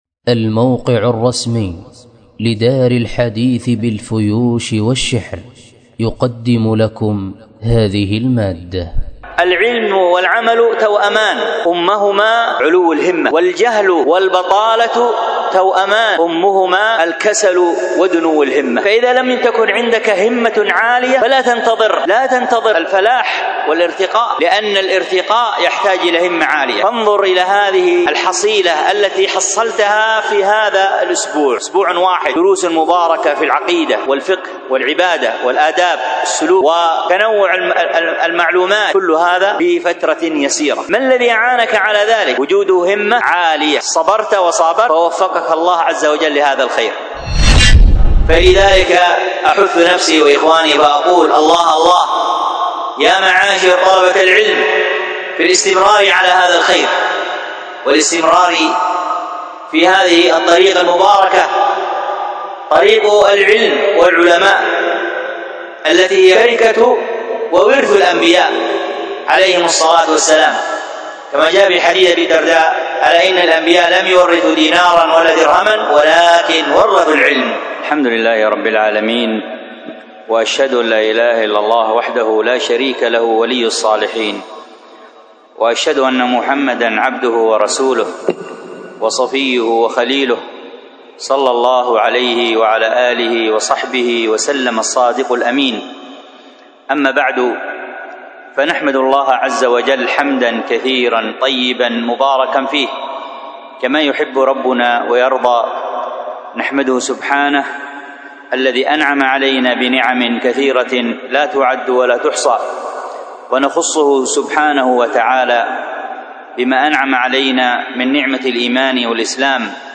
المحاضرة بعنوان الهمة في طلب العلم، والتي كانت بمسجد التوحيد بدار الحديث بتريم يوم الخميس 3 ذو القعدة 1446هـ الموافق 1 مايو 2025م